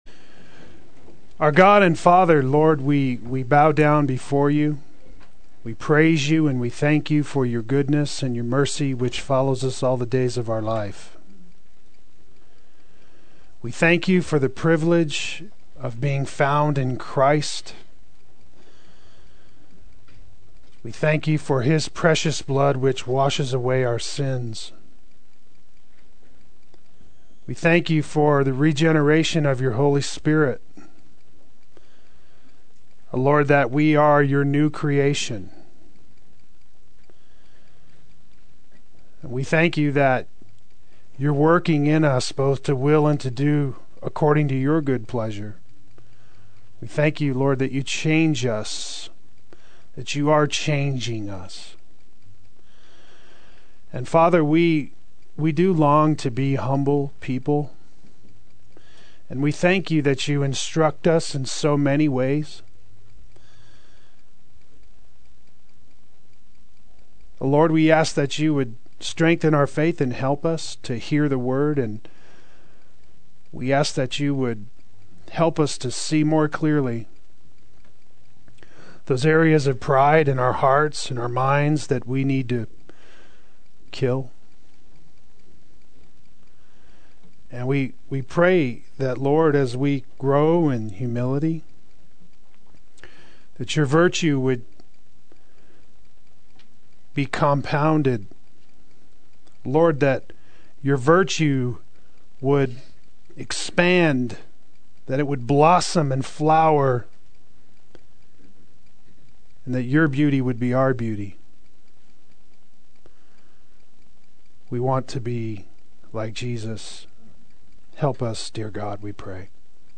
Play Sermon Get HCF Teaching Automatically.
Humble Child-Like Faith Adult Sunday School